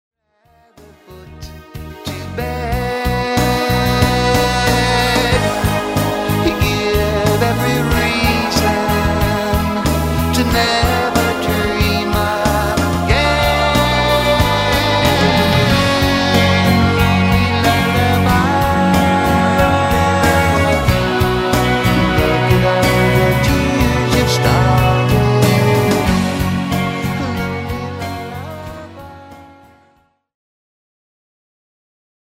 A WIDE VARIETY OF ROCK INCLUDING,